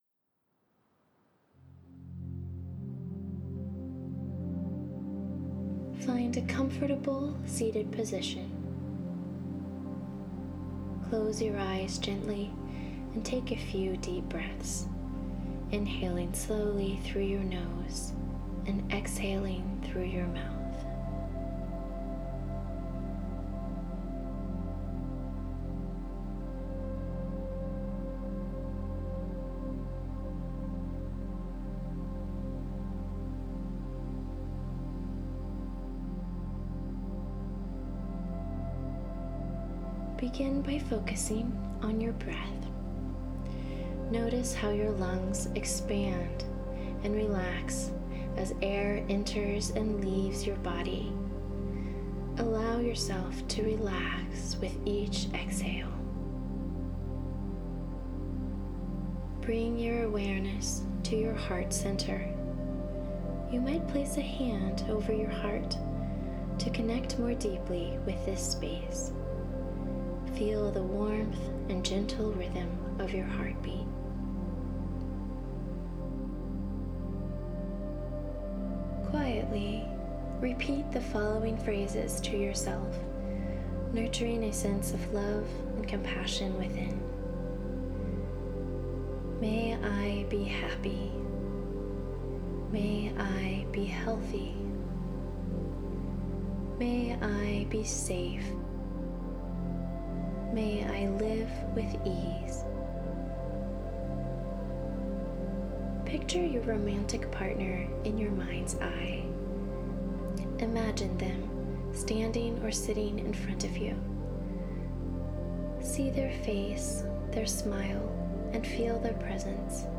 Loving-Kindness Meditation Audio
loving-kindness-meditation-audio-guidance.mp3